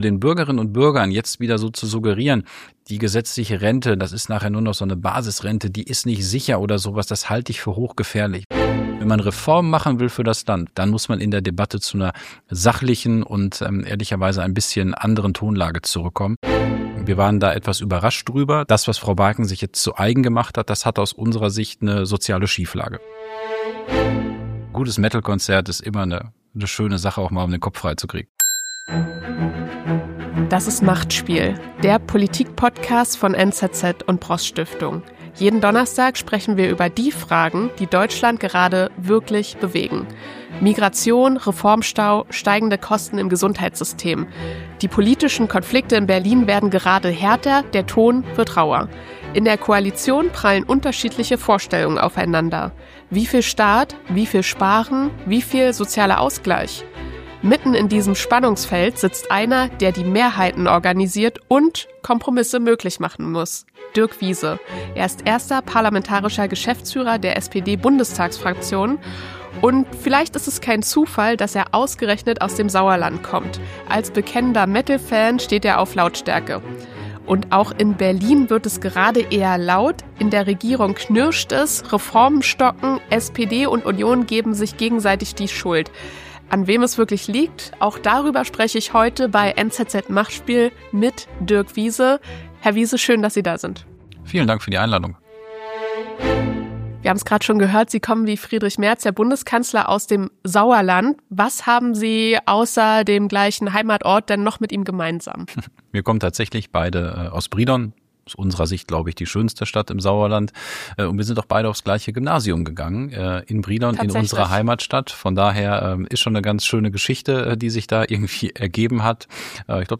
Gast: Dirk Wiese SPD, Parlamentarischer Geschäftsführer der SPD-Bundestagsfraktion